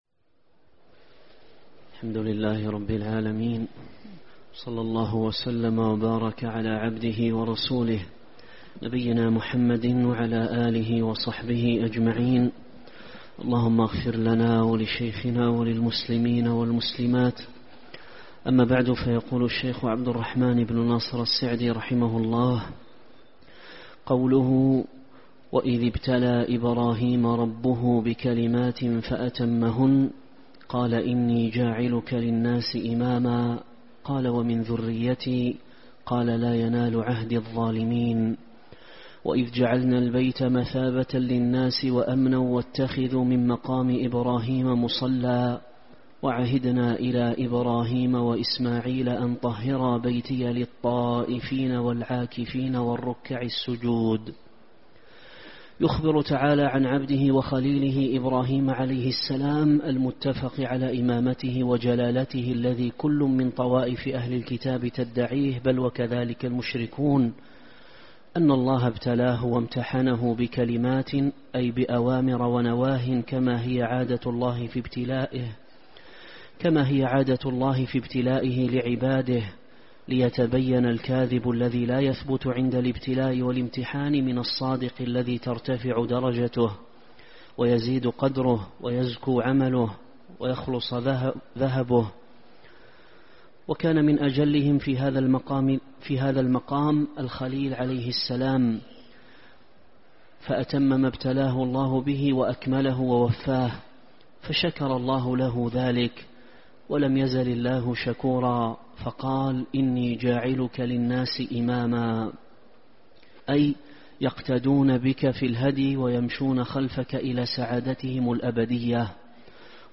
شرح تيسير الكريم الرحمن في تفسير كلام المنان الدرس 70 تفسير سورة البقرة: من الآية (١٢٤) وإذ ابتلى إبراهيم ربه بكلمات فأتمهن